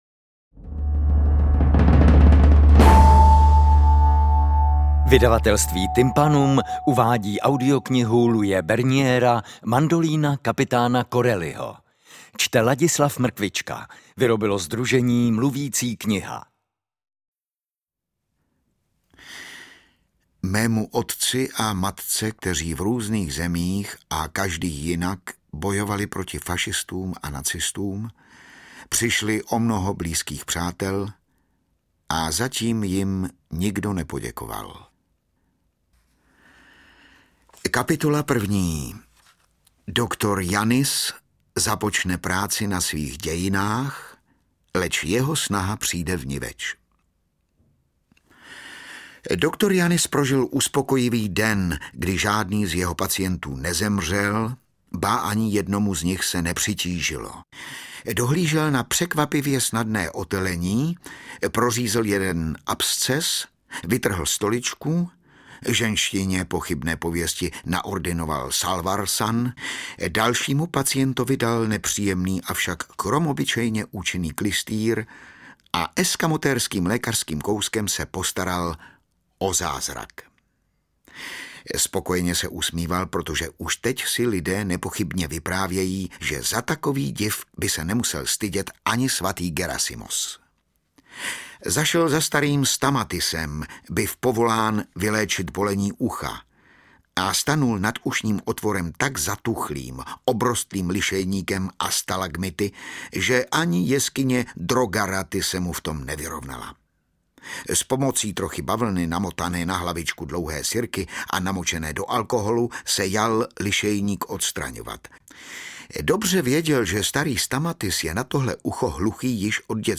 Láďa Mrkvička je báječný - tuto knihu načetl skvěle! nemohla jsem se odtrhnout
AudioKniha ke stažení, 133 x mp3, délka 22 hod. 12 min., velikost 1211,8 MB, česky